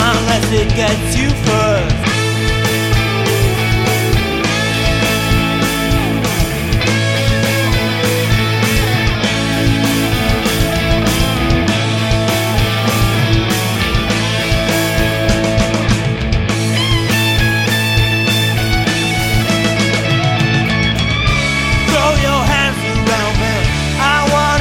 Then, for no good reason, I decided to enable both solos and pan them L/R. Would you guys have a listen and tell me what you think? This is just an edit from the bridge section, if you'd like to hear the song it's in the clinic.